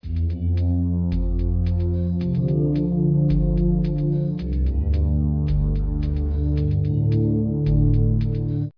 BOUCLES ET SAMPLES